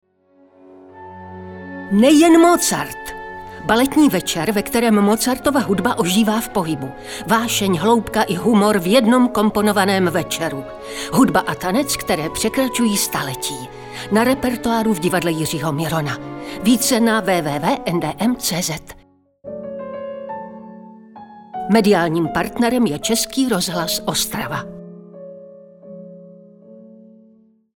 Oficiální audiospot ČRO